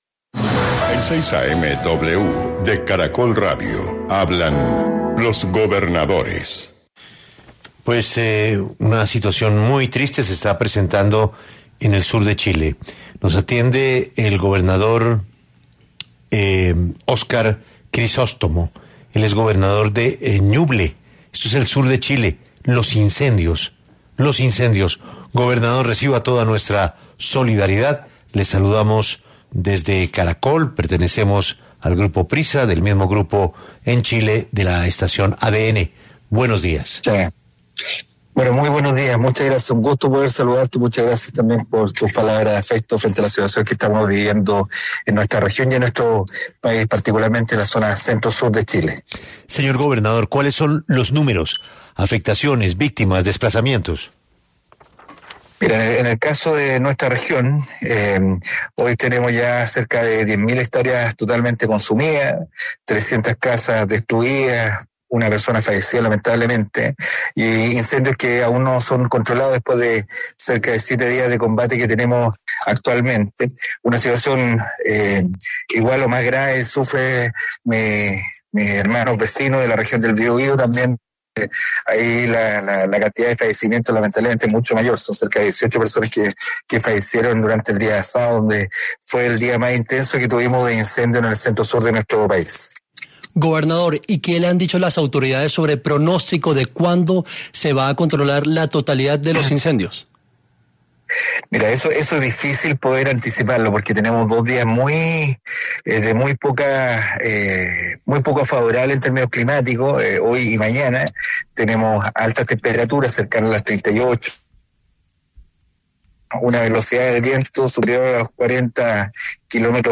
Óscar Crisóstomo, gobernador de la región de Ñuble, al sur de Chile, una de las más afectadas por los incendios en Chile, pasó por los micrófonos de 6AM W para hablar sobre las afectaciones que deja el desastre en el país austral.